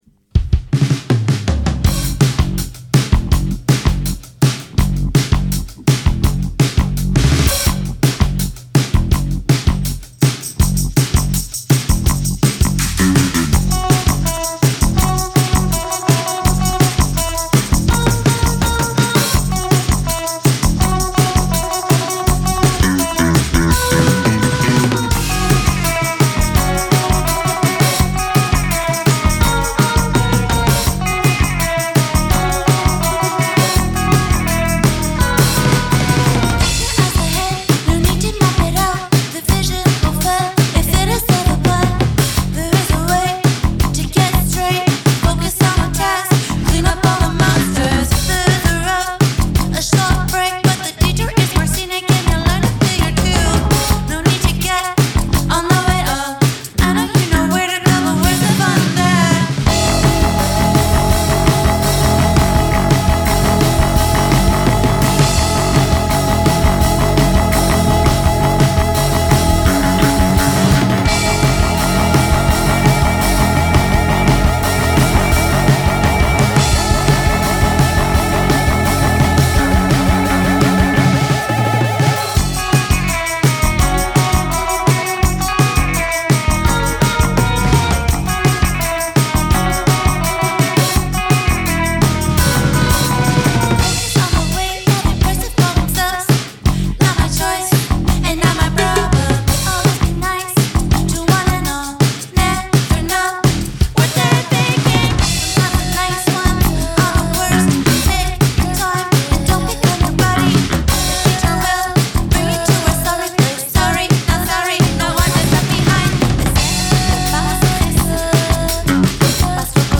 dance-punk